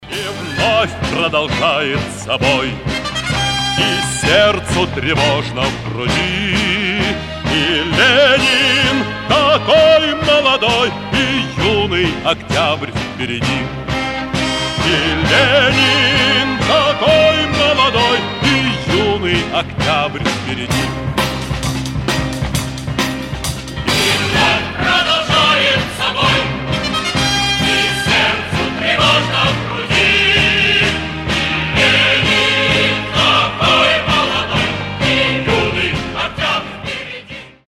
ретро
марш